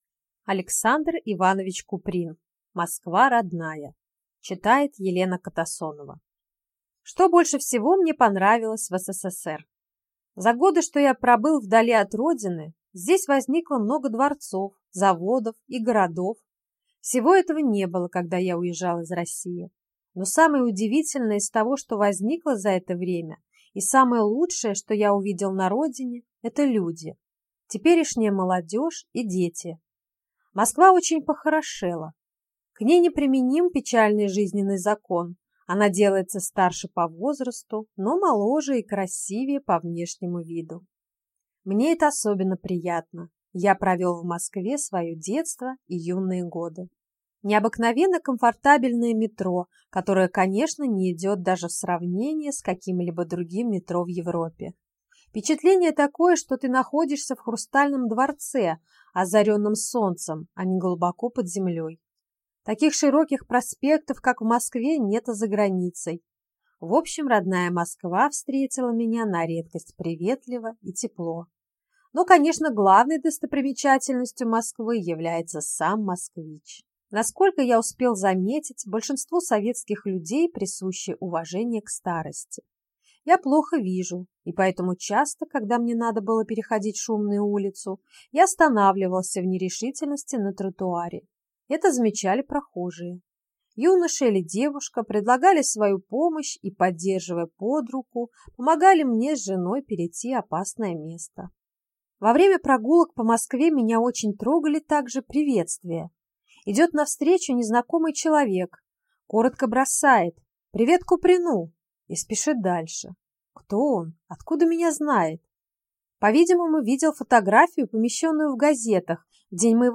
Аудиокнига Москва родная | Библиотека аудиокниг